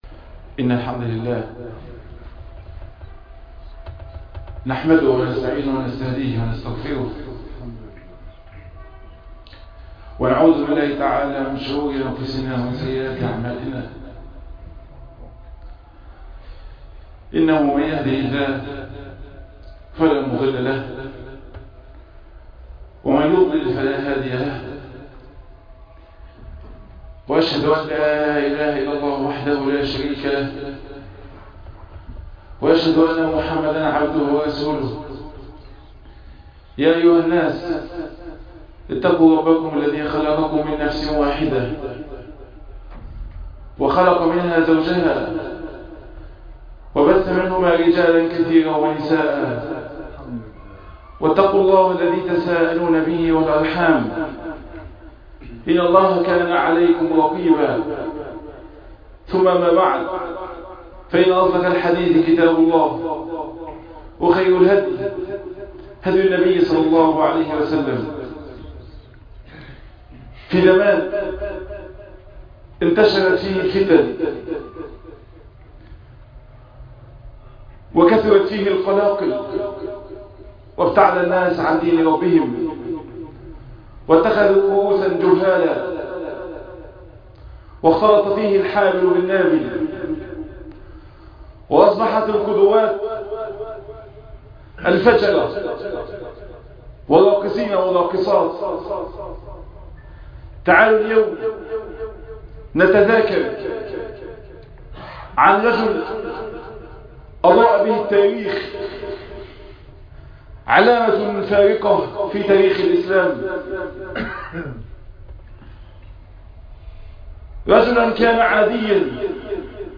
تفاصيل المادة عنوان المادة الفاروق- خطب الجمعة تاريخ التحميل السبت 11 يوليو 2020 مـ حجم المادة 20.62 ميجا بايت عدد الزيارات 323 زيارة عدد مرات الحفظ 129 مرة إستماع المادة حفظ المادة اضف تعليقك أرسل لصديق